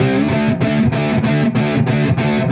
Index of /musicradar/80s-heat-samples/95bpm
AM_HeroGuitar_95-D01.wav